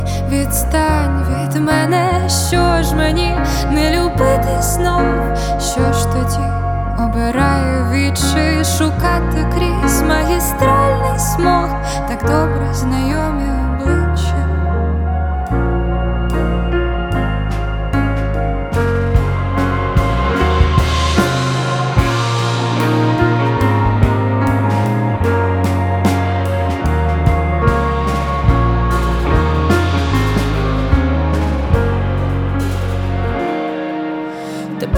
Indie Pop Alternative
Жанр: Поп музыка / Альтернатива / Украинские